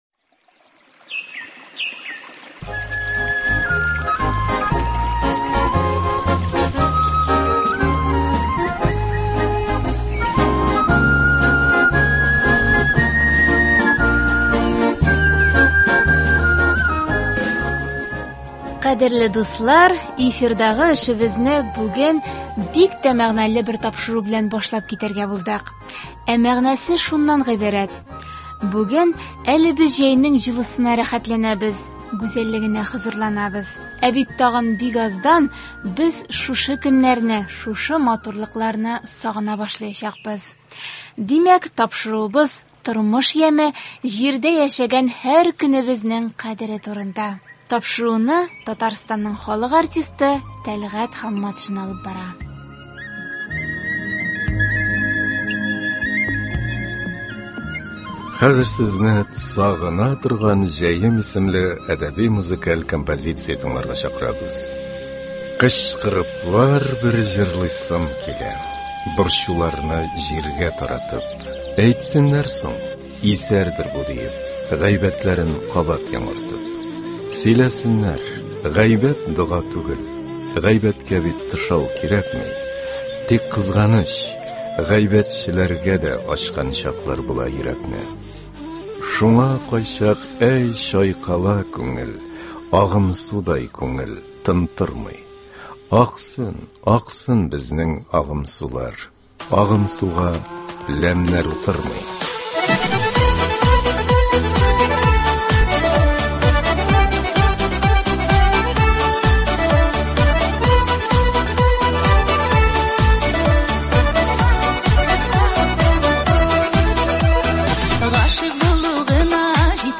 “Сагына торган җәем” дигән әдәби-музыкаль композиция.